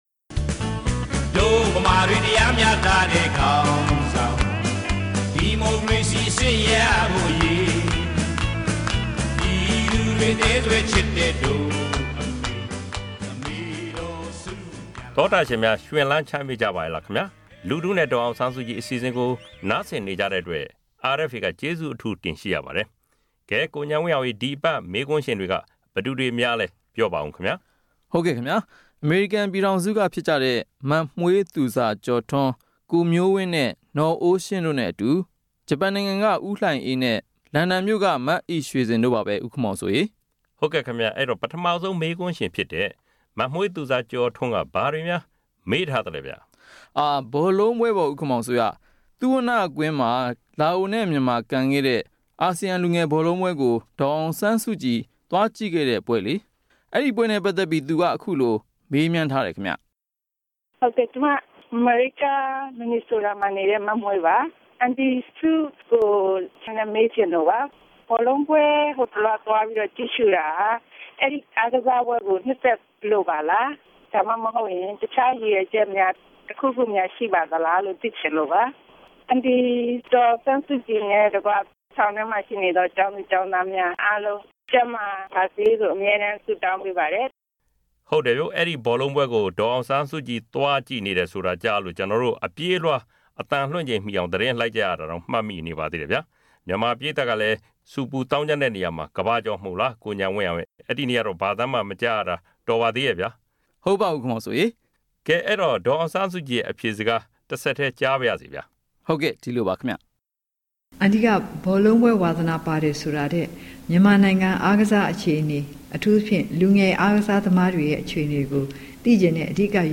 ‘လူထုနှင့် ဒေါ်အောင်ဆန်းစုကြည်’ အပတ်စဉ်အမေးအဖြေ